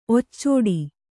♪ occōḍi